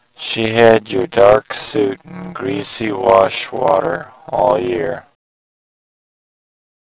Warped sound with 33% Overlap